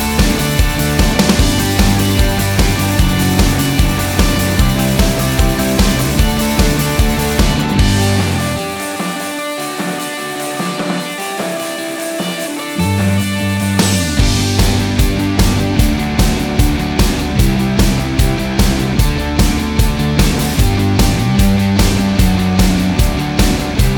no Backing Vocals Rock 3:19 Buy £1.50